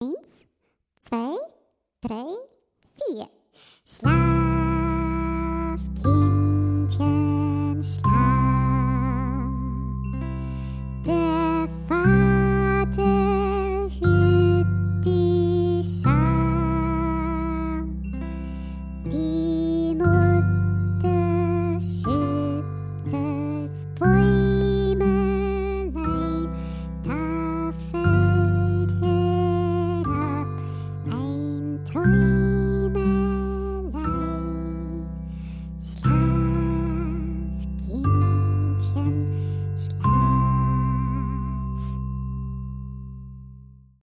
Mit Gesang